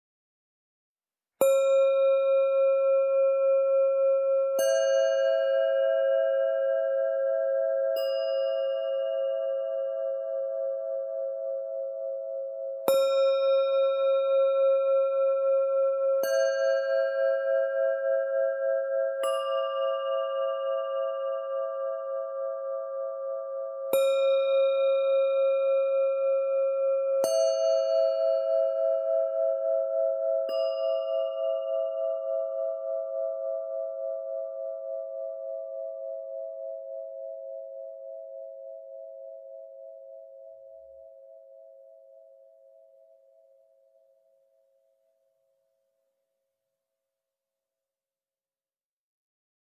Just as the ocean rests in perfect stillness when the wind ceases, the sound of the Nagi Singing Bowls invites you into a state of quiet presence. This set includes three small singing bowls with a high, clear tone that gently resonates when tapped with the enclosed beater. Their pure, shimmering sound is ideal for initiating and concluding meditation sessions, marking a transition into deep stillness and mindful awareness.